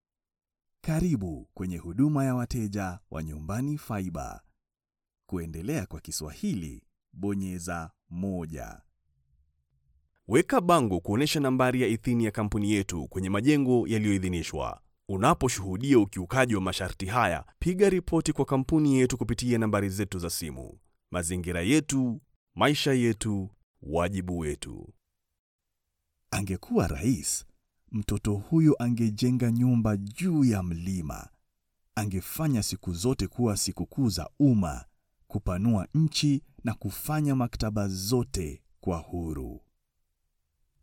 Scarlett 2i2 studio
DeepLow
MatureWarmFunnyAuthoritativeEmotional